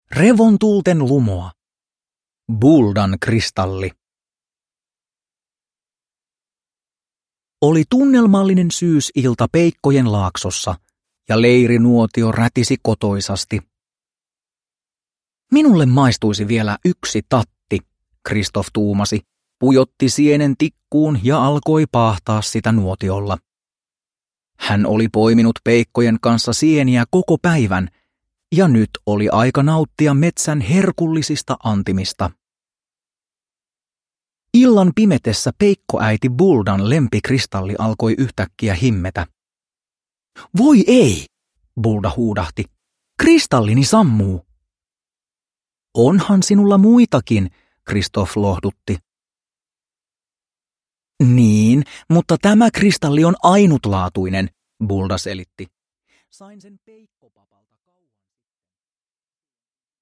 Buldan kristalli – Ljudbok – Laddas ner
Uppläsare: Antti L. J. Pääkkönen